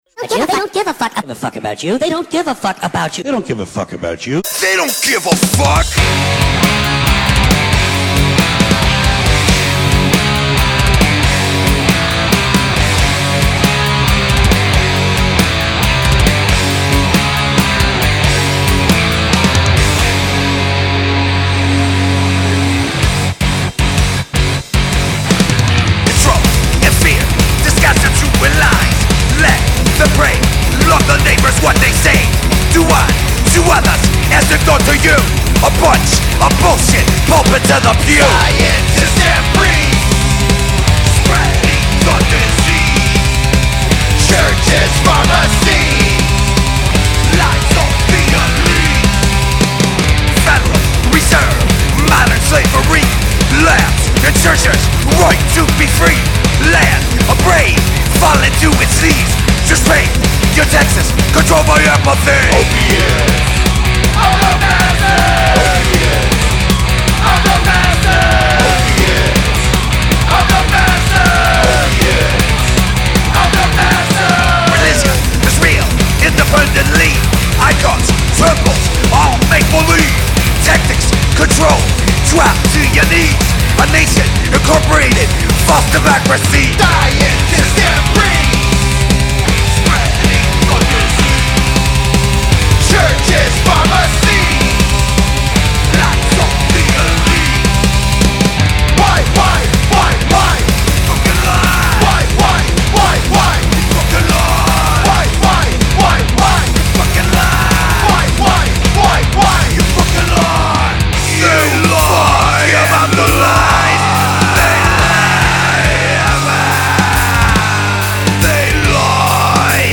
Genre: Metal.